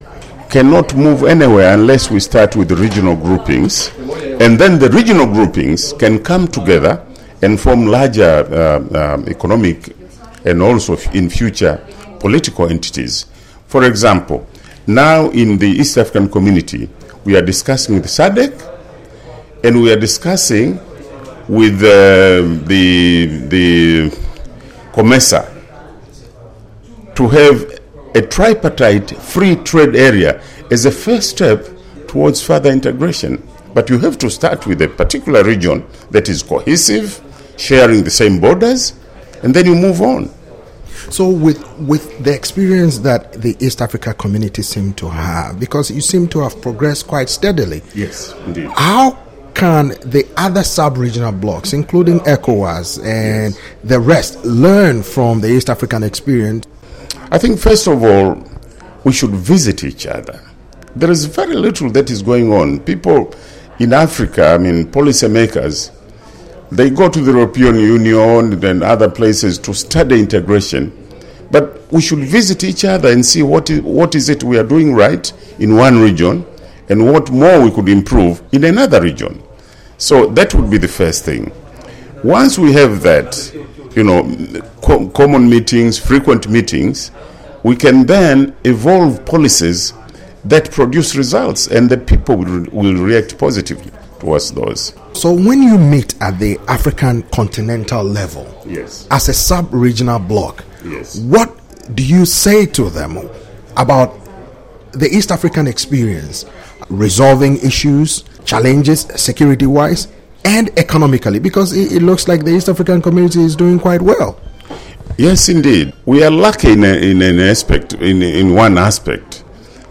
intv